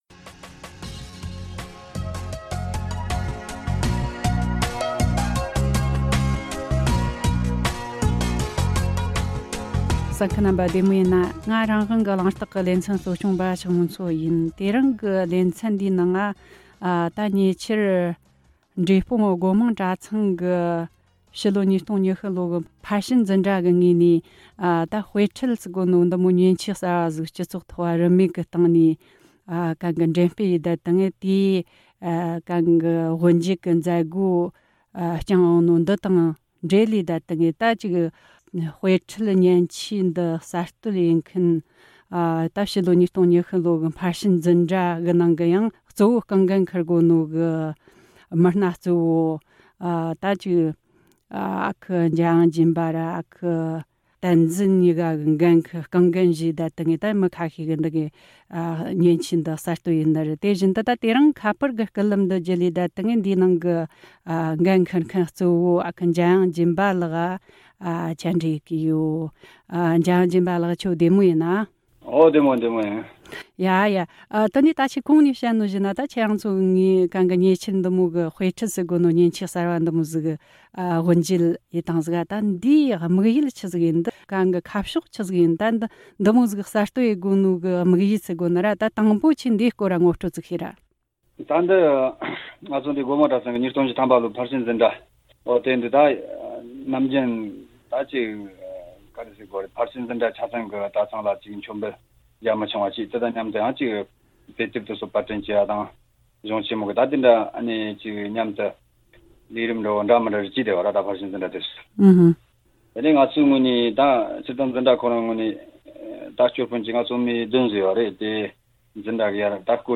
བཅར་འདྲི་བྱས་བར་གསན་རོགས་གནོངས།